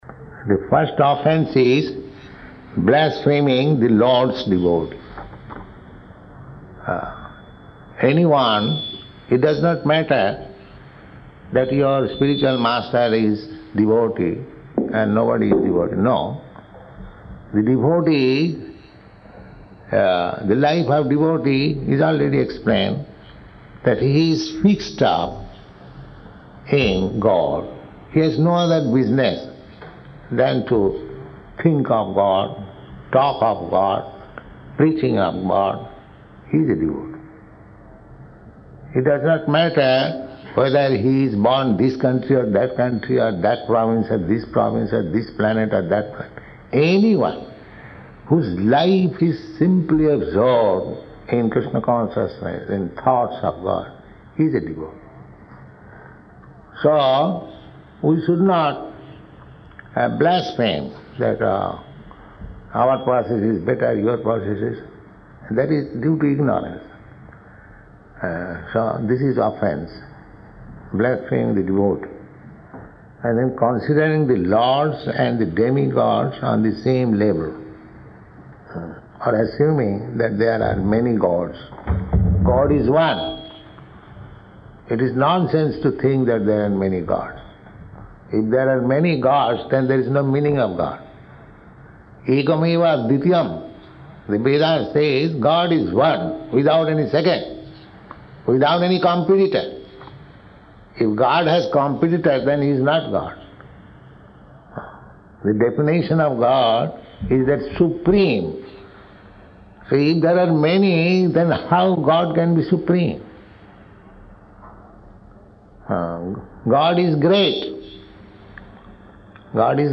Ten Offenses Lecture
Ten Offenses Lecture --:-- --:-- Type: Initiation Dated: February 22nd 1970 Location: Los Angeles Audio file: 700222IN-LOS_ANGELES.mp3 Prabhupāda: The first offense is blaspheming the Lord’s devotee.